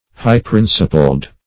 Meaning of high-principled. high-principled synonyms, pronunciation, spelling and more from Free Dictionary.
Search Result for " high-principled" : Wordnet 3.0 ADJECTIVE (1) 1. having high principles ; The Collaborative International Dictionary of English v.0.48: High-principled \High"-prin`ci*pled\, a. Possessed of noble or honorable principles.
high-principled.mp3